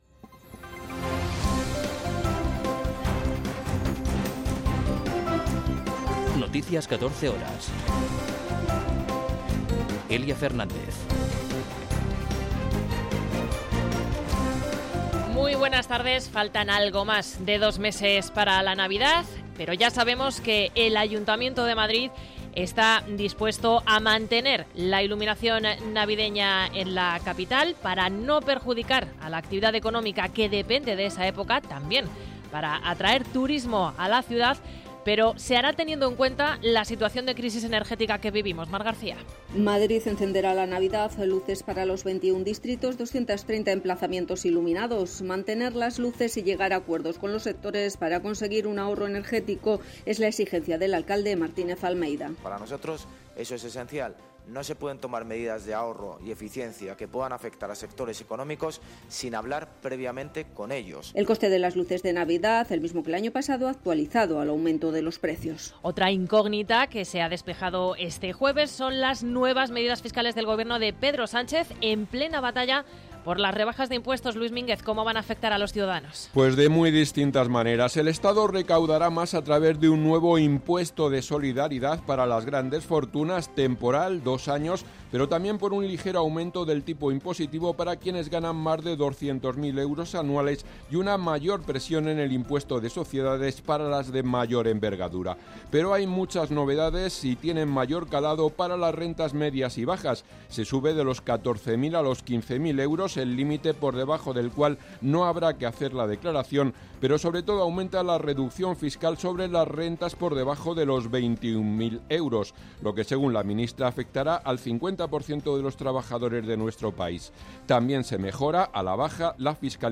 Noticias 14 horas 29.09.2022